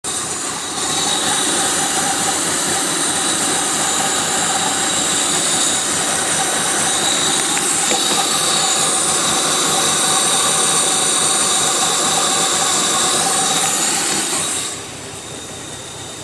bon apparemment je ne sais pas mettre fichier mp3, le bruit que j'entends c'est un bruit fort d'aspiration qui vient de l’intérieur du papillon pour aller dans le tuyau de l'IAVC
C'est normal qu'il y ait un bruit d'aspiration.
moteurmgtf~0.mp3